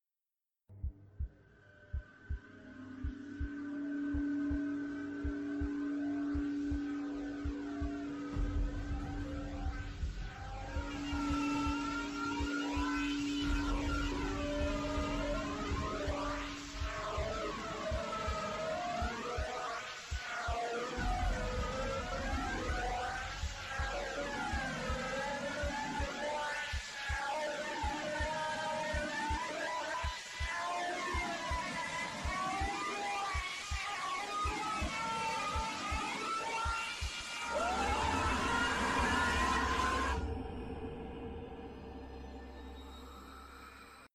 JHcw1bjWEvc_Scp-096-Screaming.mp3